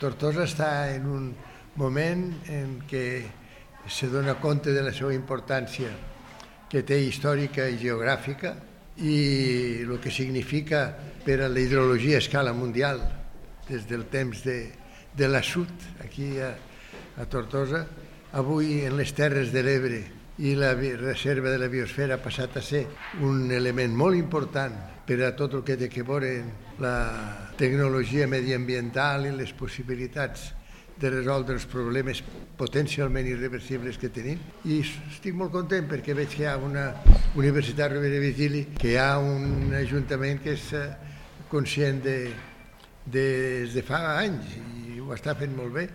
El president de la Fundació Cultura de Paz i exdirector General de la Unesco, Federico Mayor Zaragoza, ha presidit l’acte de lliurament dels premis que porten el seu nom i que s’ha celebrat aquest dissabte al Teatre Auditori Felip Pedrell i que convoquen l’Associació d’Amigues i Amics de Tortosa per la Unesco i la URV.